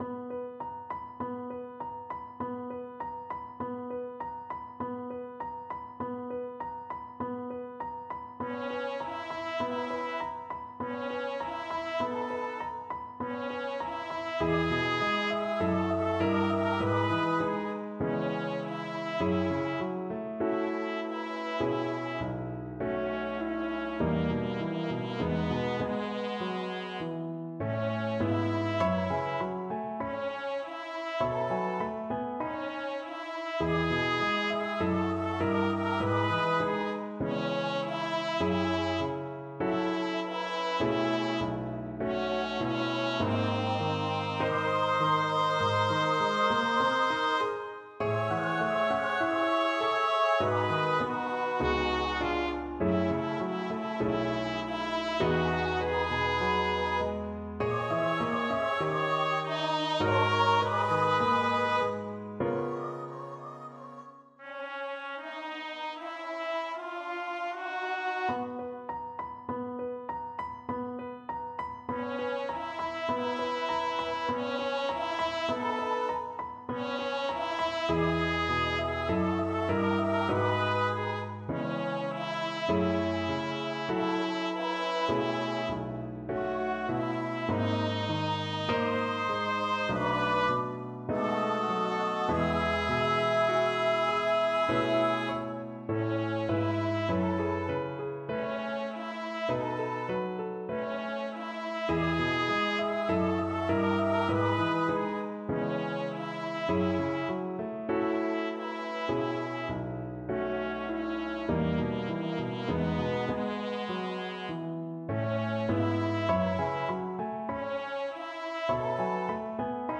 00_wonka-Pure-Imagination-vs-for-mp3-rehearsal-s2_unknown.mp3